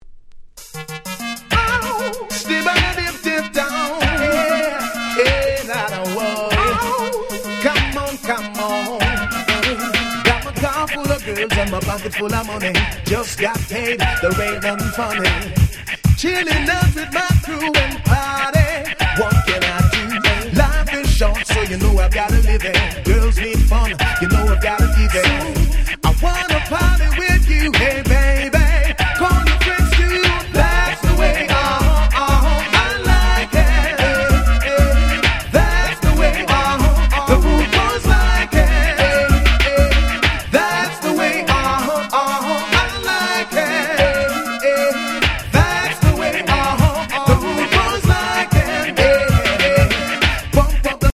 90's Dancehall Reggae Classic Riddim !!